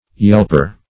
Yelper \Yelp"er\, n.
yelper.mp3